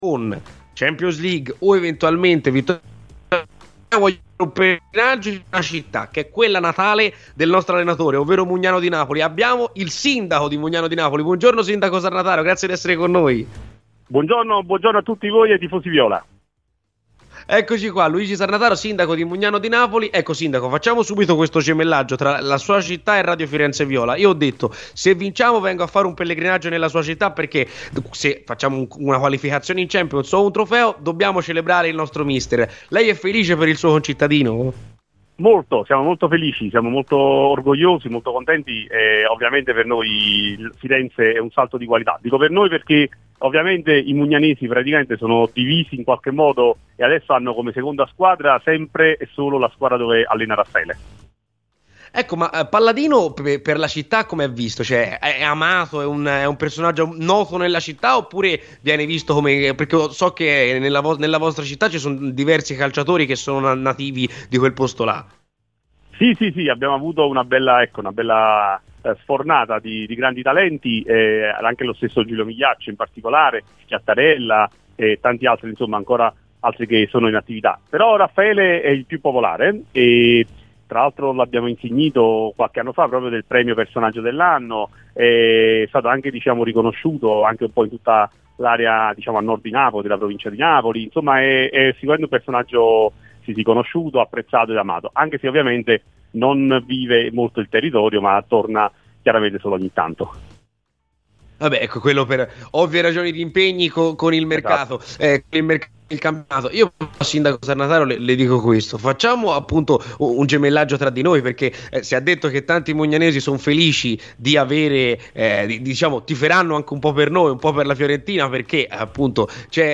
Il sindaco di Mugnano di Napoli - paese natale di Raffaele PalladinoLuigi Sarnataro, nel corso di "C'è polemica" su Radio FirenzeViola, ha parlato dell'attuale allenatore della Fiorentina, a cui è tuttora legato viste le origini: “Siamo molto felici e orgogliosi di Raffaele. Firenze è un salto di qualità, i mugnanesi ora hanno come seconda squadra quella in cui lavora Raffaele, per cui, in questo momento, la Fiorentina”.